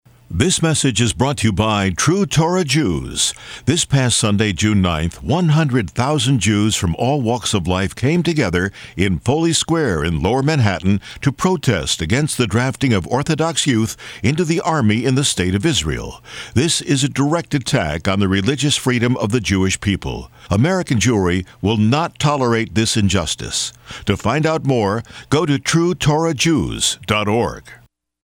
Radio Advertisements